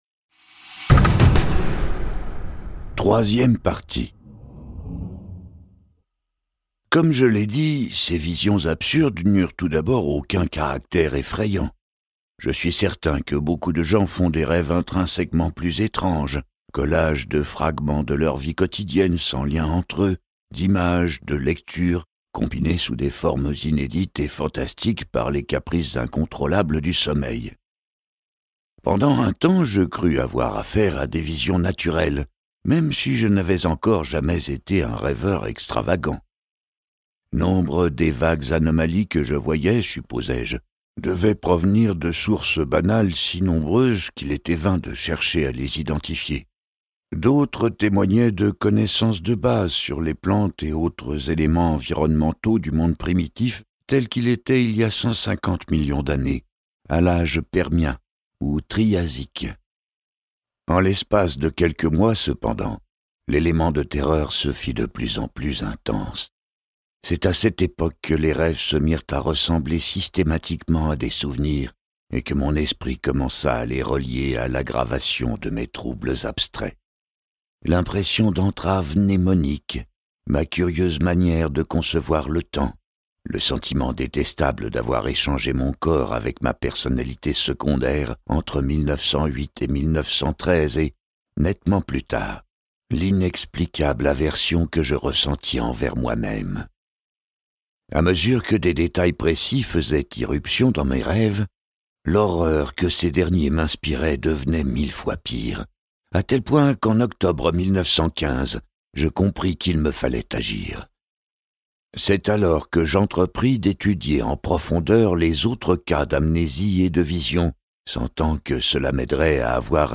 Le mythe de Cthulhu n'a jamais été aussi réel…Ce livre audio est interprété par une voix humaine, dans le respect des engagements d'Hardigan.